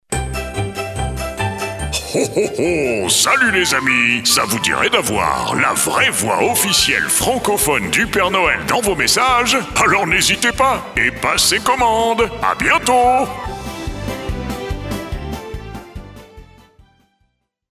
La vraie voix du Père Noël arrive sur notre site !
Offrez un peu de magie à vos visiteurs avec la voix authentique du Père Noël, enregistrée par nos comédiens professionnels.
Messages personnalisés, vidéos de vœux, répondeurs enchantés ou campagnes publicitaires : chaque mot résonne avec la chaleur du sapin, le crépitement du feu et un brin de clochette dans l’air.
Que ce soit pour une marque, ou un événement, notre service vous permet d’intégrer le timbre légendaire du Père Noël directement à vos projets.